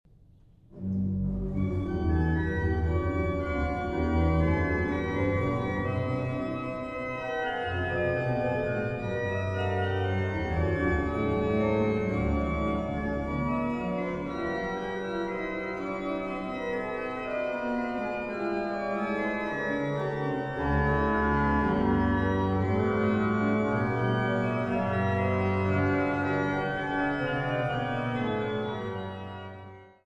Buchholz-Orgel Nikolaikirche Stralsund